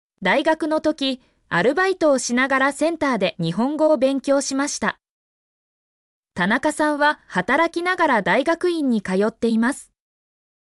mp3-output-ttsfreedotcom-32_uNGW6tzD.mp3